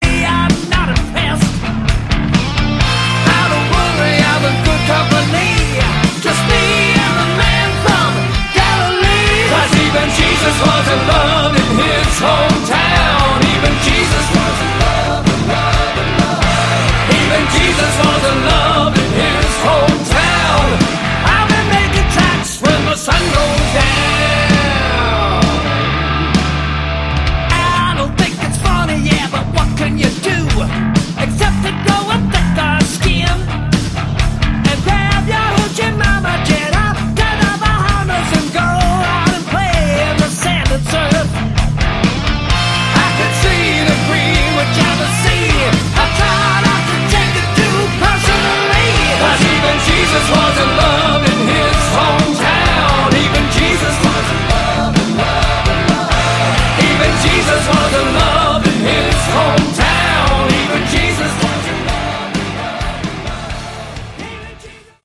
Category: Hard Rock
vocals
guitar, vocals
bass, vocals, keyboards
drums